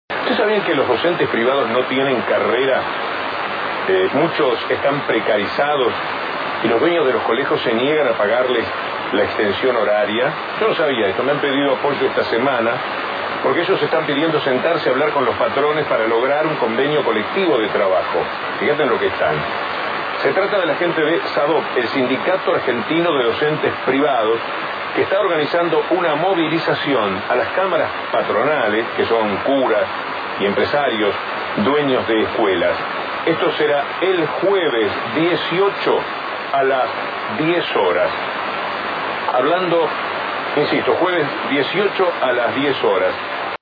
El programa de radio que conduce el periodista Víctor Hugo Morales, "La Mañana" por Continental AM 590, informa sobre la marcha que realizará el Sindicato el jueves 18 de octubre a las Cámaras Patronales.